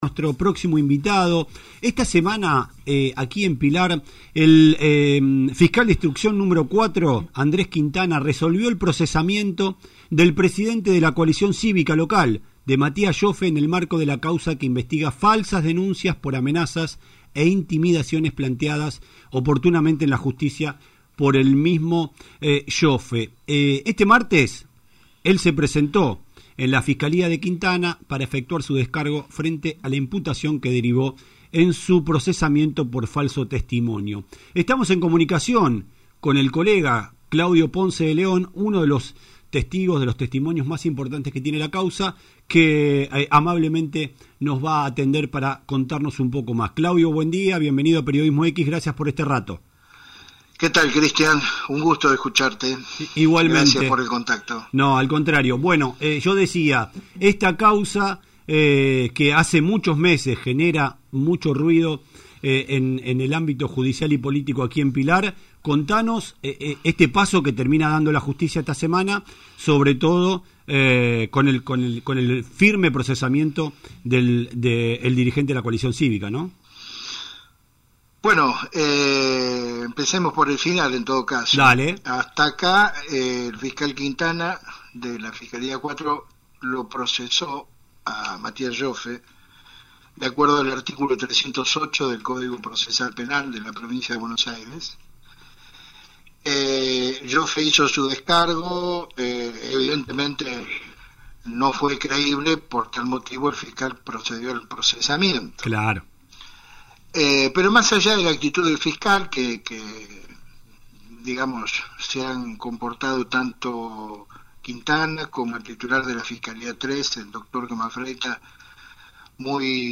Entrevistado por Periodismo X